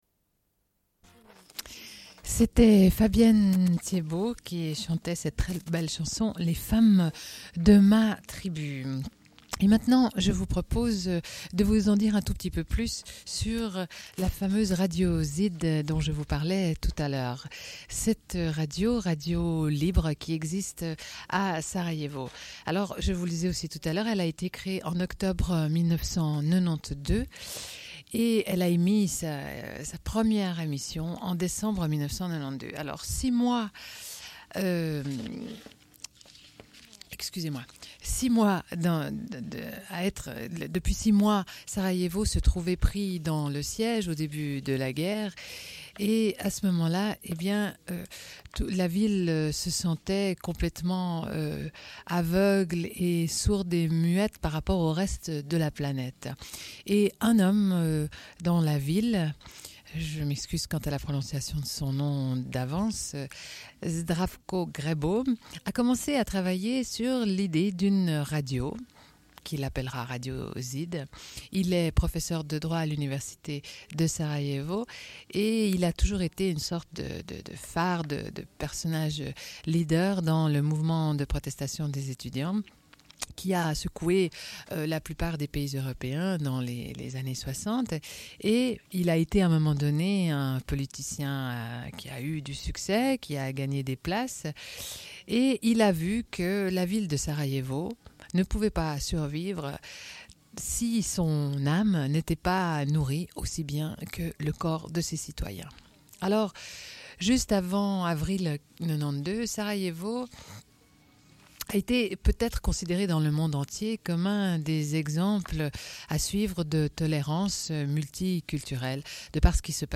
Une cassette audio, face B28:59